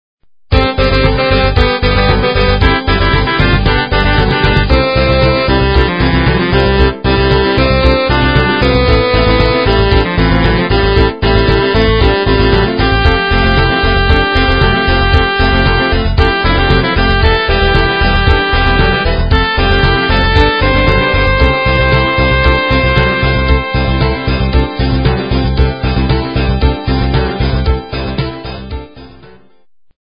Polyphonic Ringtones